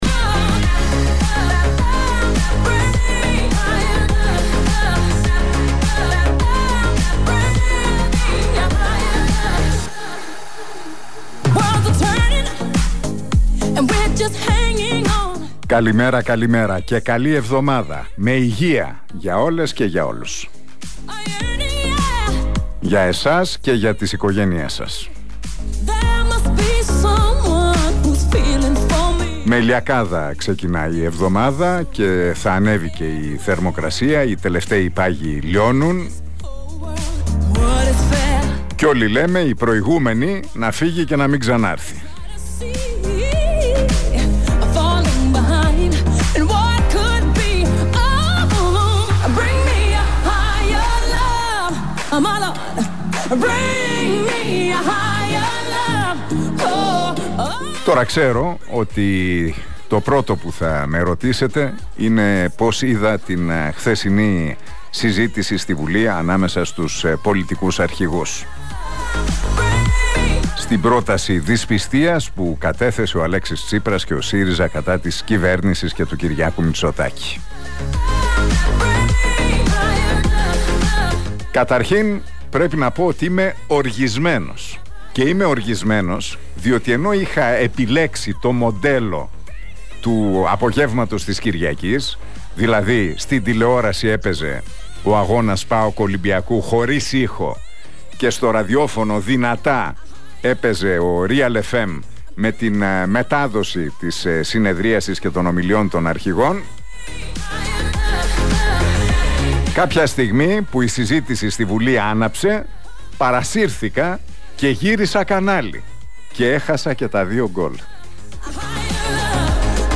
Ακούστε το σημερινό σχόλιο του Νίκου Χατζηνικολάου στον Realfm 97,8.